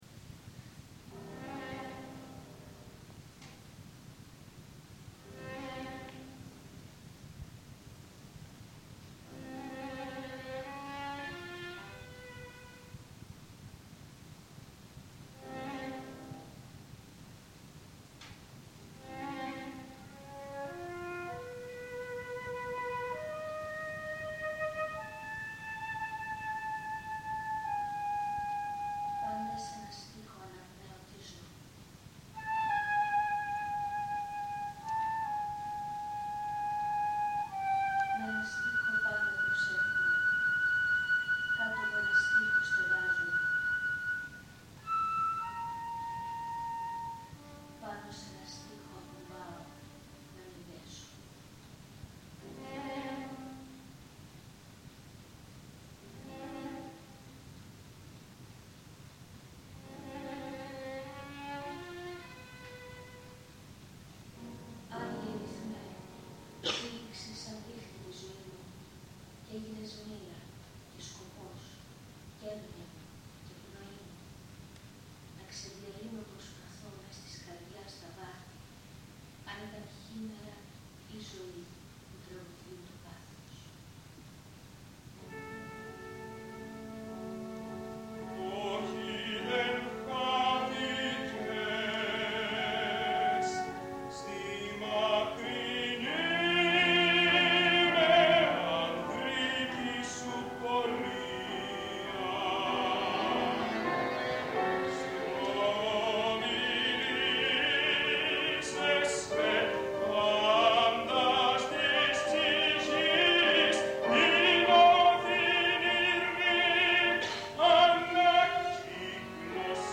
Βαρύτονος